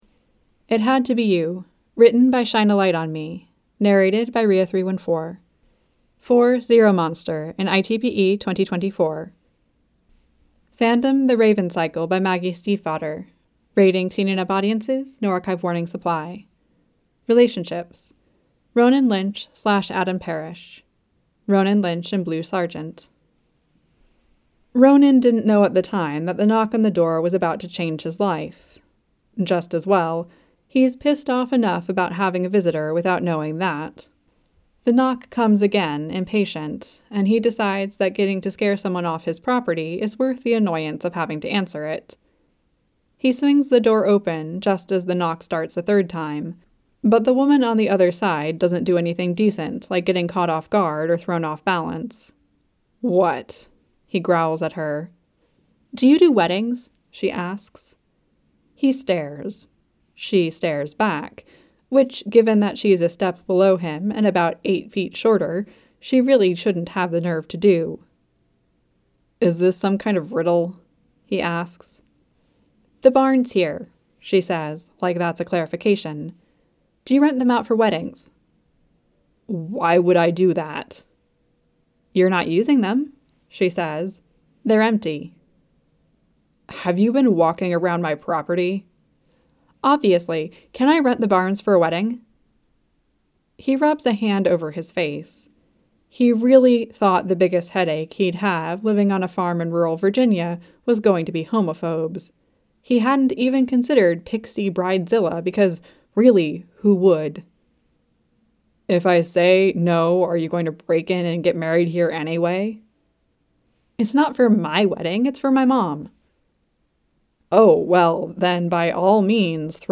[Podfic]